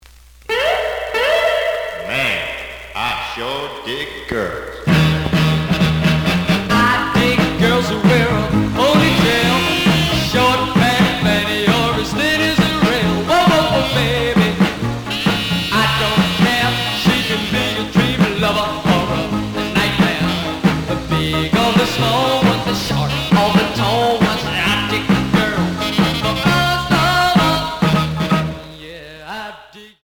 試聴は実際のレコードから録音しています。
●Genre: Rhythm And Blues / Rock 'n' Roll
●Record Grading: VG (両面のラベルにダメージ。)